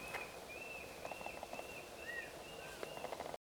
Borralhara (Mackenziaena severa)
Nome em Inglês: Tufted Antshrike
Localidade ou área protegida: Reserva Privada y Ecolodge Surucuá
Condição: Selvagem
Certeza: Gravado Vocal